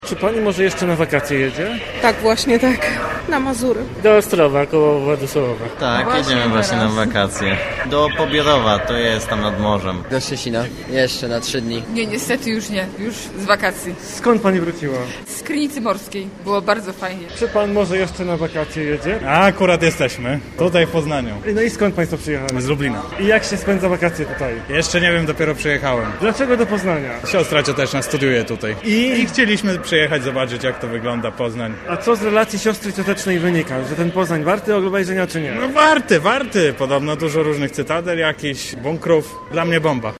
Z sondy naszego reportera wynika, że liczymy jeszcze na dobrą pogodę i bardzo chętnie jedziemy na północ kraju.